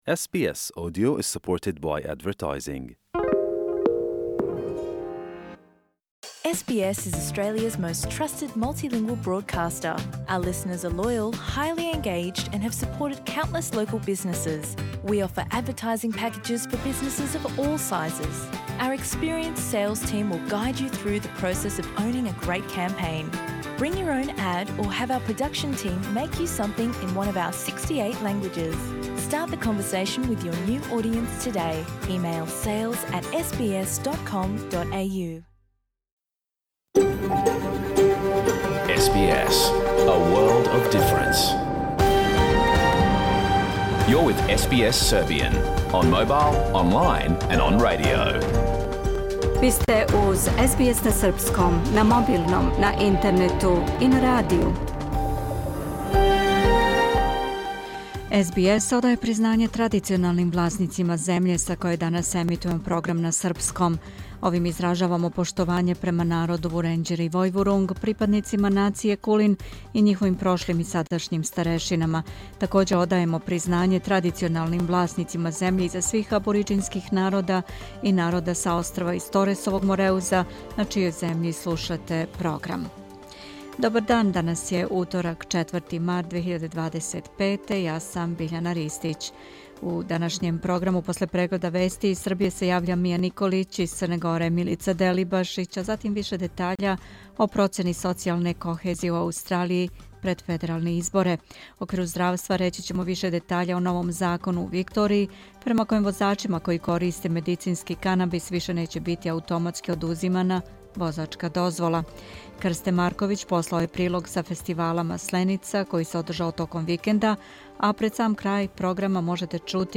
Програм емитован уживо 4. марта 2025. године
Уколико сте пропустили данашњу емисију, можете је послушати у целини као подкаст, без реклама.